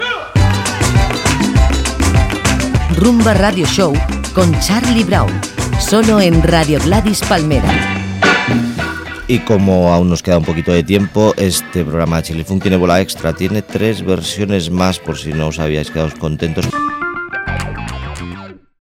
Indicatiu del programa i tema musical.
Musical